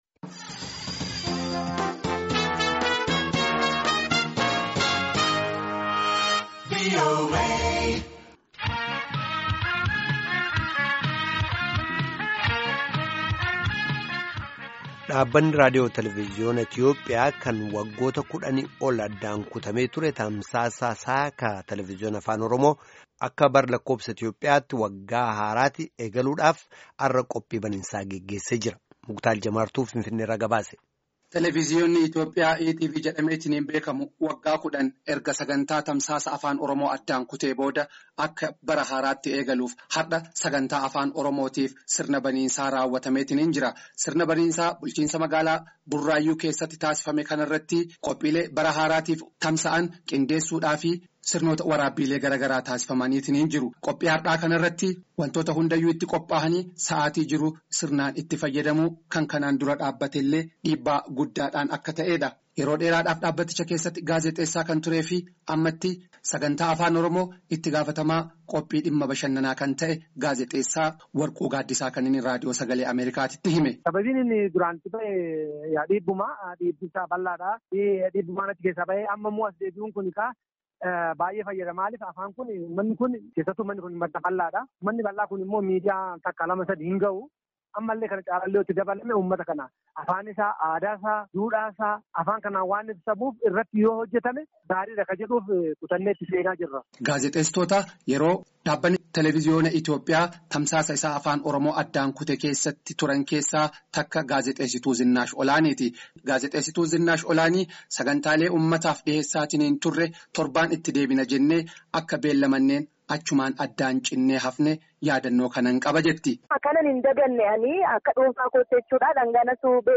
Finfinnnee irraa gabaase